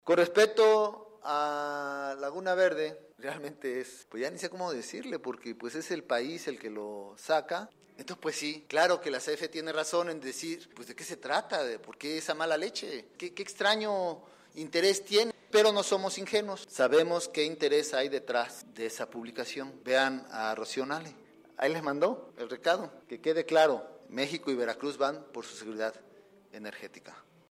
Redacción/Veracruz.- La Central Nucleoeléctrica de Laguna Verde no representa ningún riesgo, y eso lo sabe hasta un estudiante de ingeniería en Mecánica-Eléctrica de segundo semestre, puntualizó el gobernador Cuitláhuac García Jiménez en conferencia de prensa.